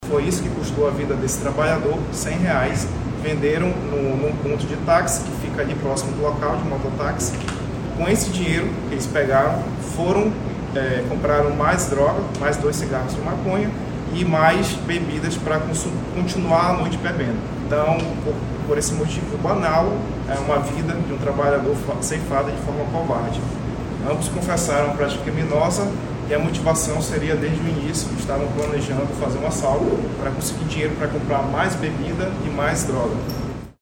Em coletiva de imprensa nesta quarta-feira